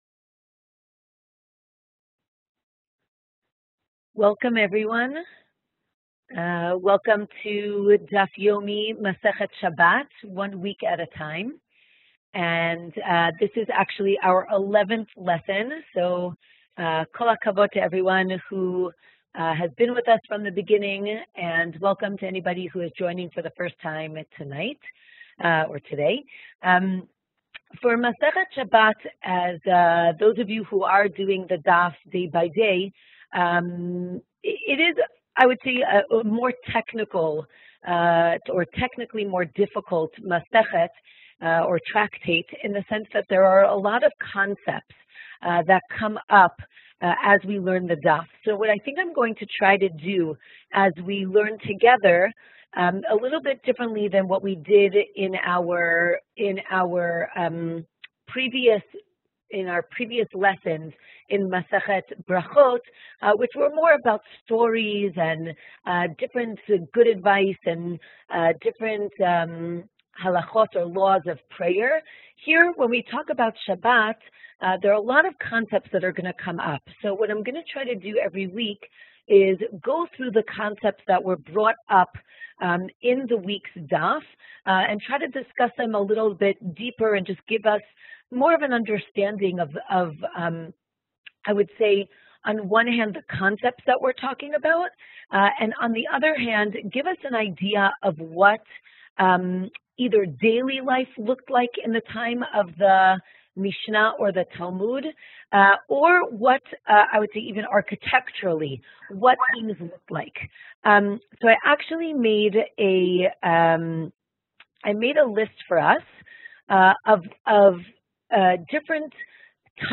To join the live class register on WebYeshiva